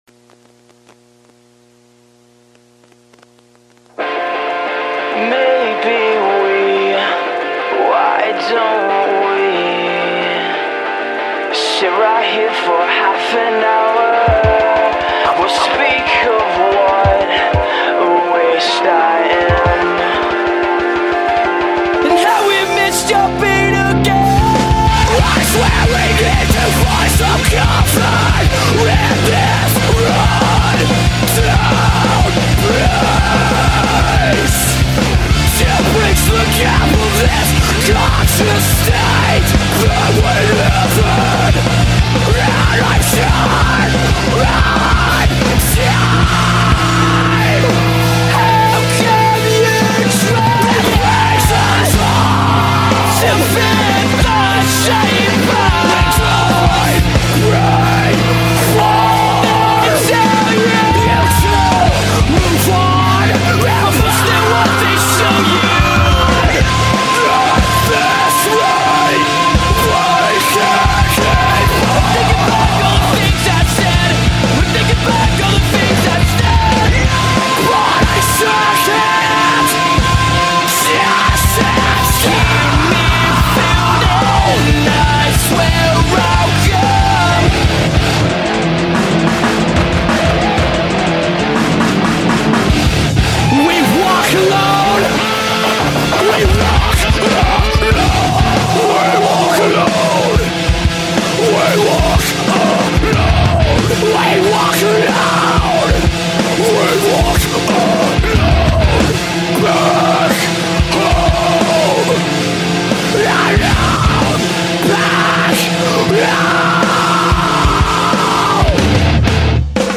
Категория: Rock, Alternative